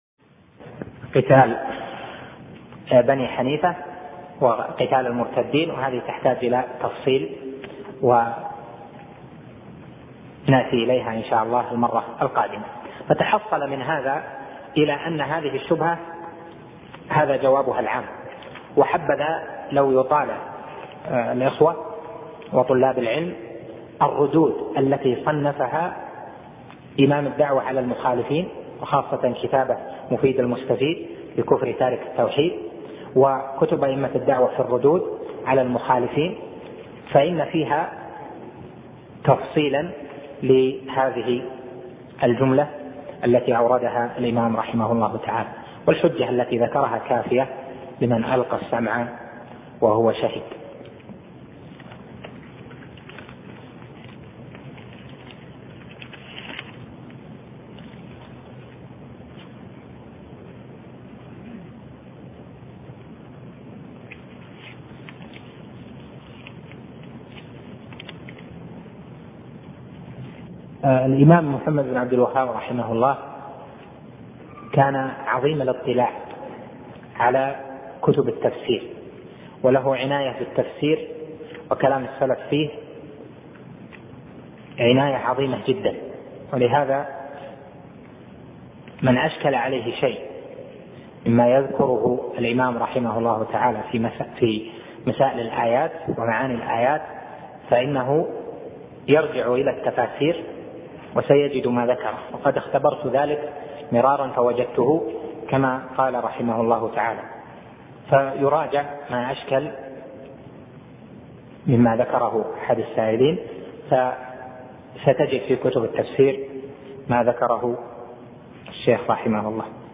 الدرس الثاني عشر